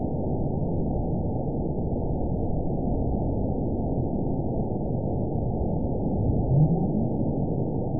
event 917878 date 04/19/23 time 11:26:46 GMT (2 years ago) score 9.36 location TSS-AB04 detected by nrw target species NRW annotations +NRW Spectrogram: Frequency (kHz) vs. Time (s) audio not available .wav